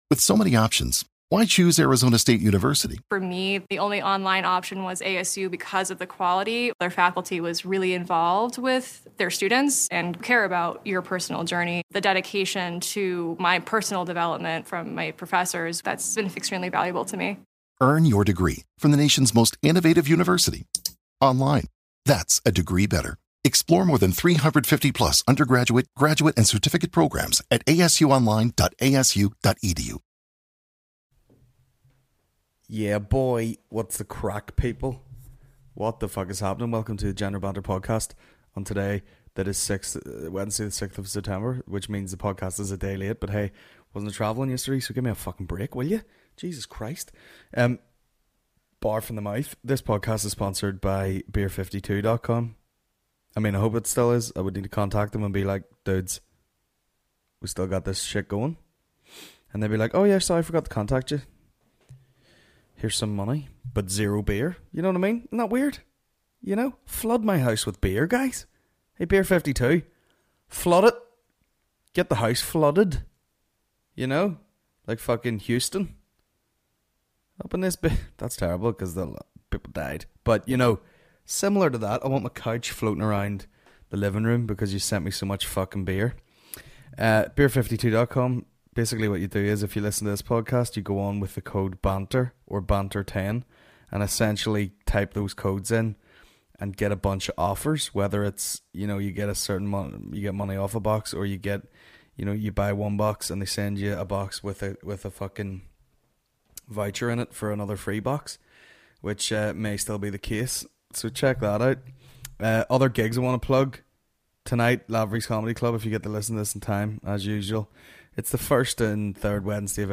Wednesday 6th September 2017 General Banter Podcast General Banter Podcast Comedy 4.8 • 1.1K Ratings 🗓 6 September 2017 ⏱ 122 minutes 🔗 Recording | iTunes | RSS 🧾 Download transcript Summary This week - White Privilege and Gay Germans .